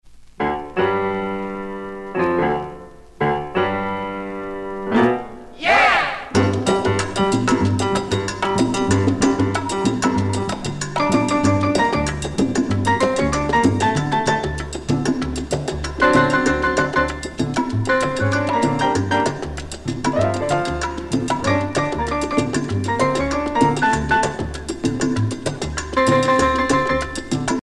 danse : mambo
Pièce musicale éditée